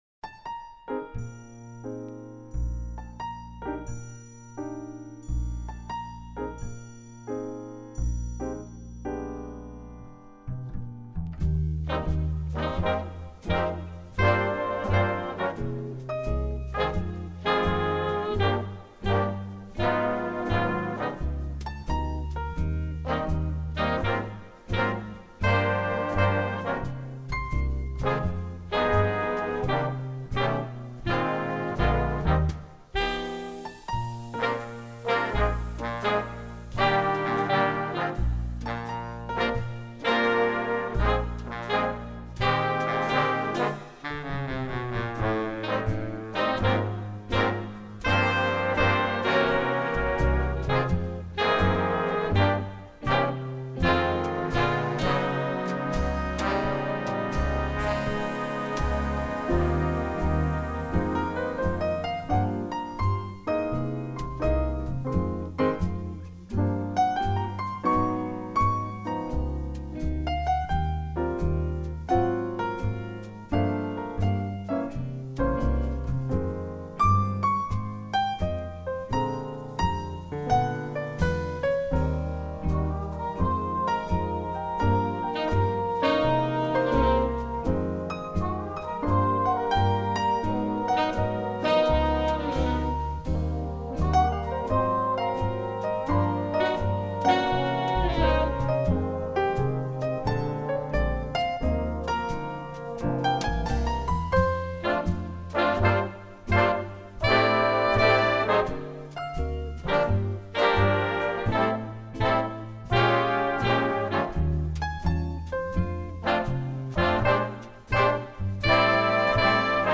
Instrumentation: jazz band
children, classical, folk, traditional, instructional